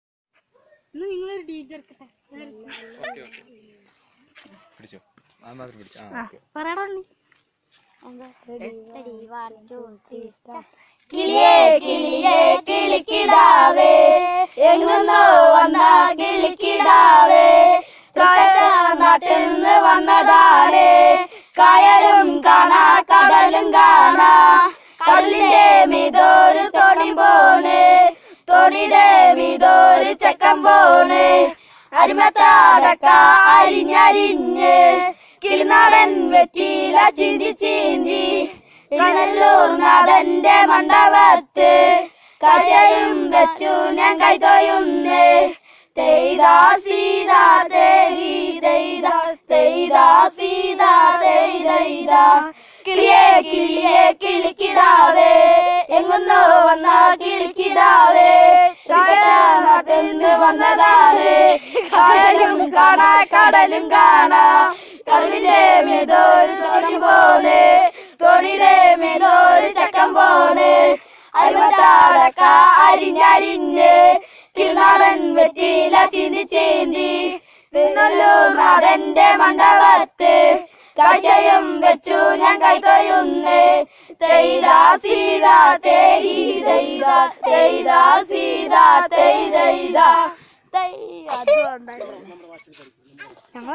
Performance of folk song
The song is about a bird, a boy, Thriunellur god. The song is sung by the kids of the community.